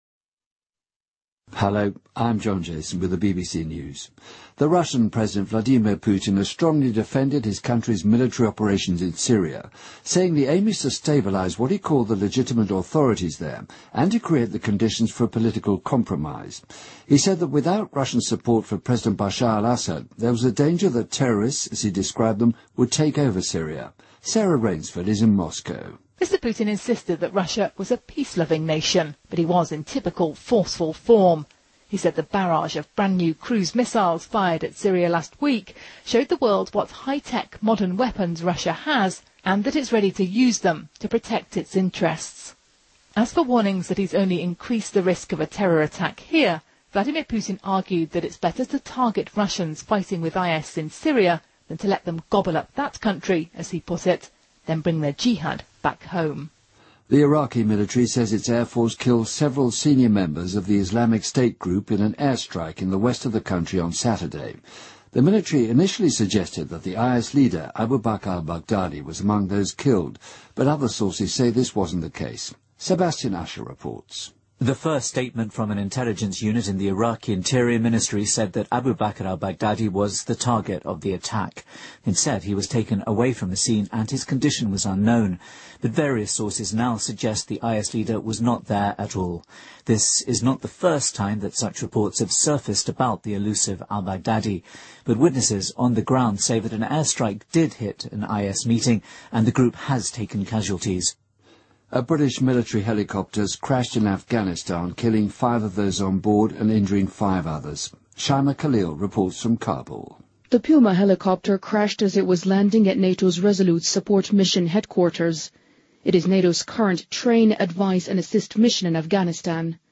BBC news,卢卡申科第5次当选白俄罗斯总统
日期:2015-10-13来源:BBC新闻听力 编辑:给力英语BBC频道